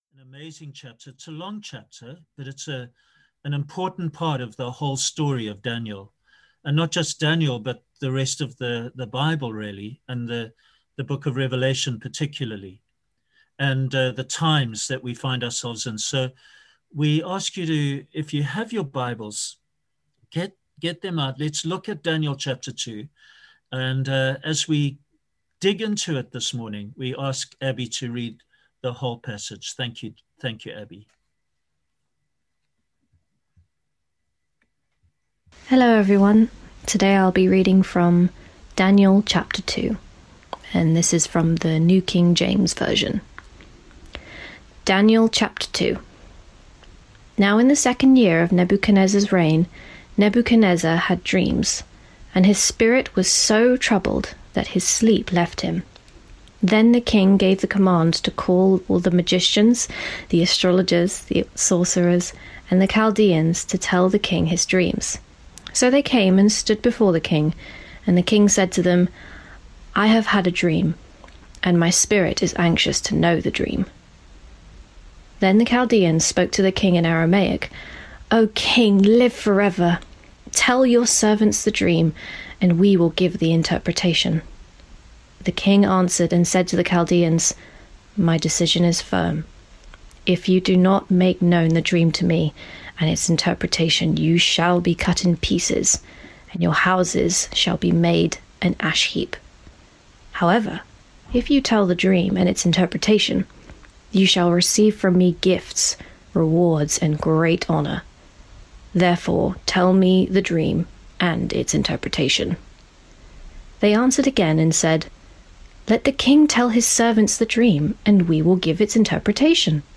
Below is the recording of the sermon for this week.